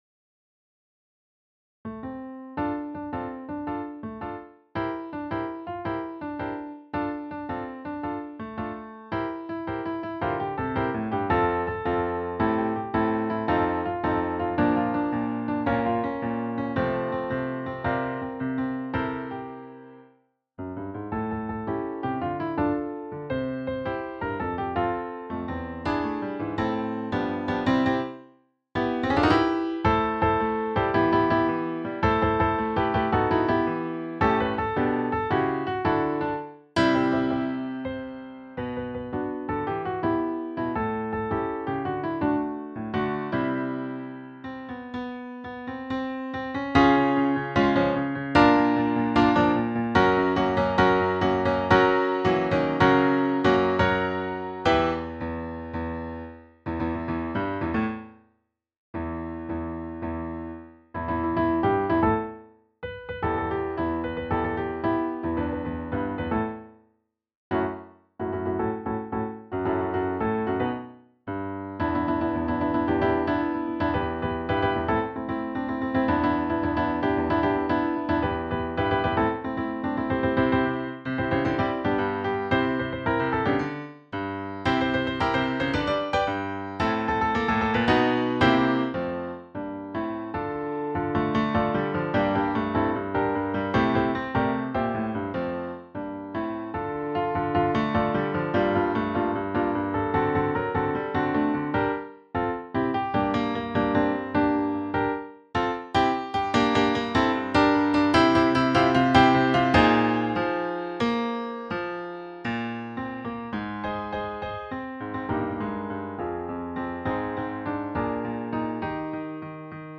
A Cappella Arrangement
Scoring: SSATB
Audio Sample (MIDI):